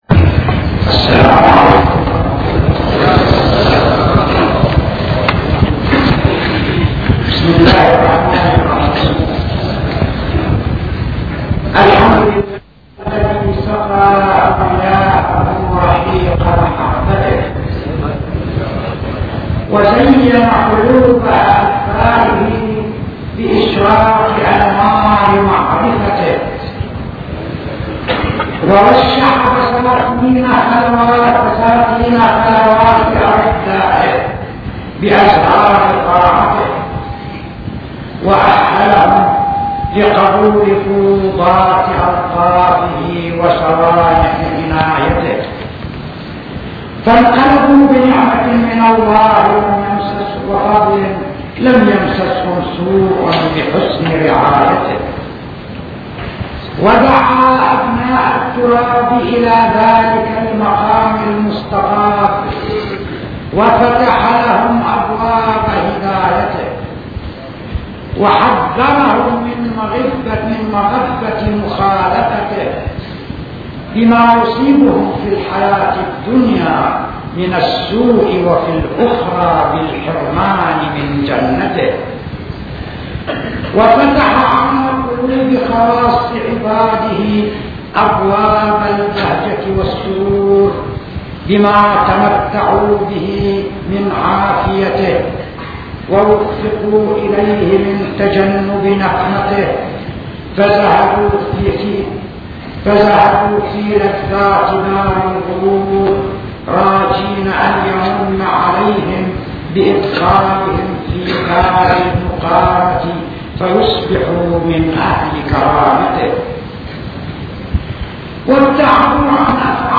آثار المعصية – مولد الإمام الجواد عليه السلام – 1993م